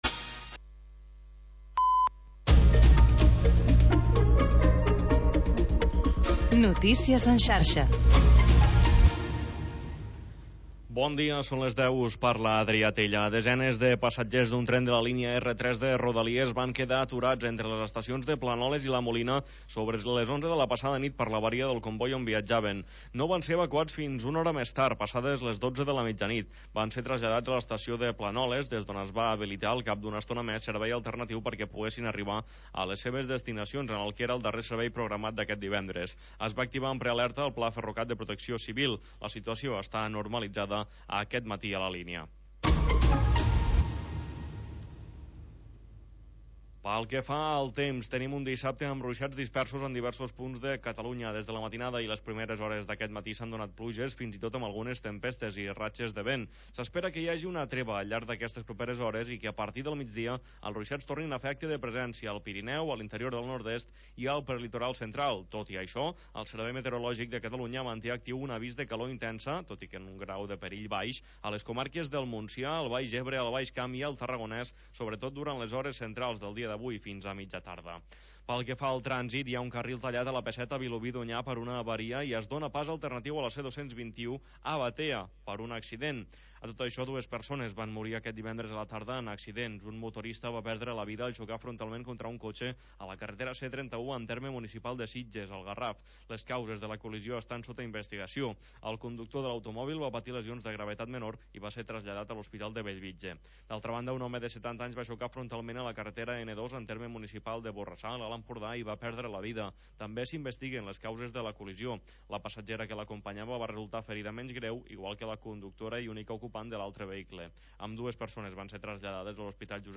Programa sardanista, amb actualitat, compositors i agenda de ballades